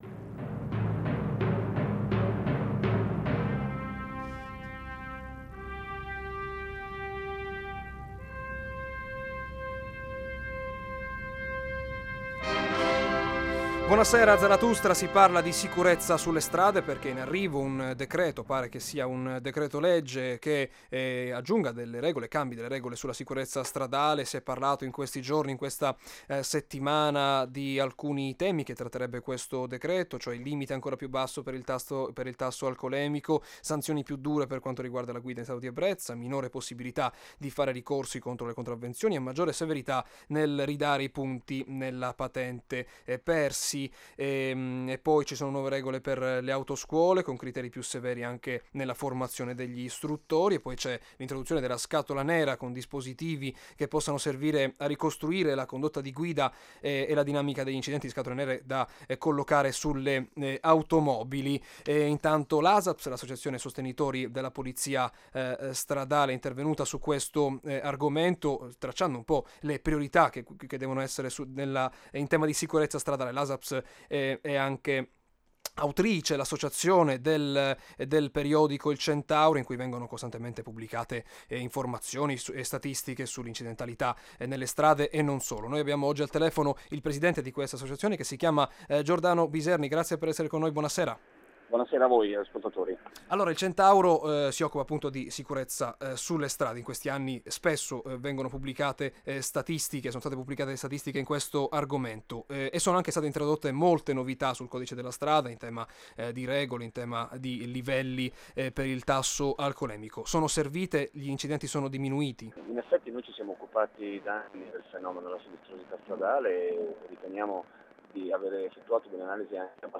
Ascolta la puntata di Zarathustra andata in onda sabato 28 marzo, alle 18,05, su Radio Italia anni '60 - Emilia Romagna.